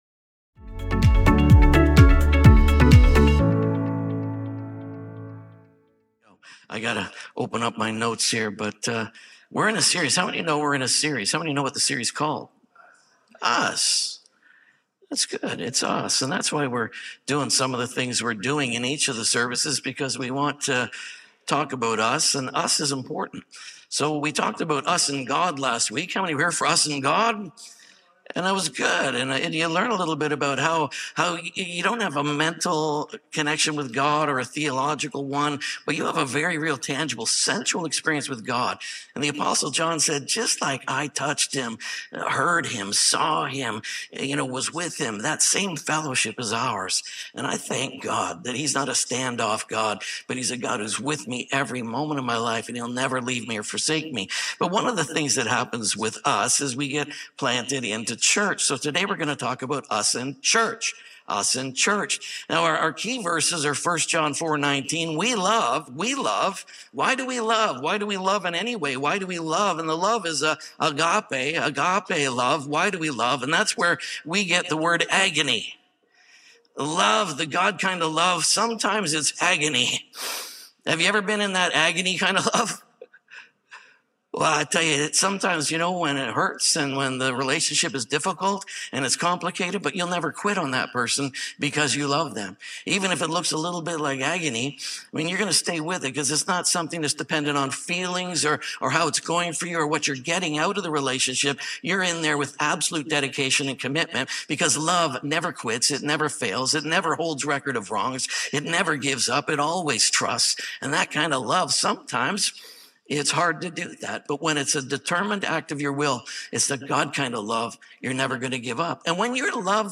Us and Church | Us Series | SERMON ONLY .mp3